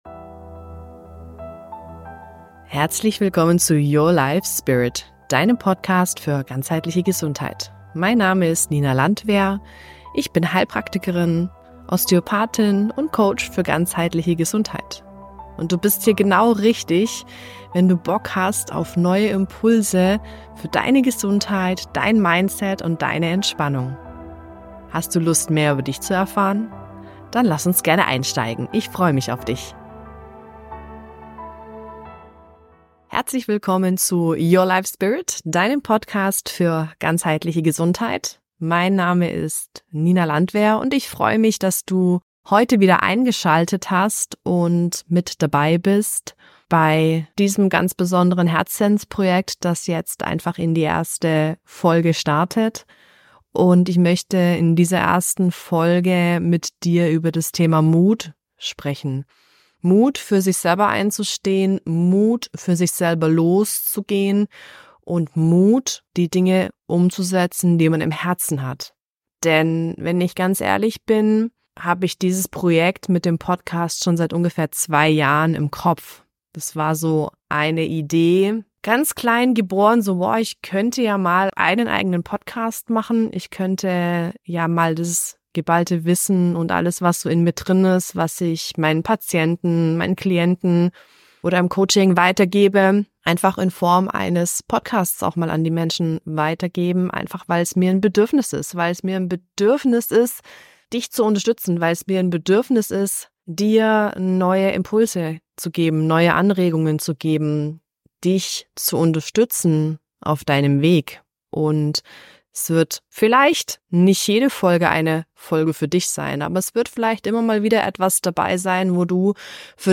Ganz intuitiv und ohne Skript möchte ich mit dir gemeinsam über das Thema Mut sprechen. Was ist Mut und vor allem warum ist es so wichtig mutig seinen Weg zu gehen?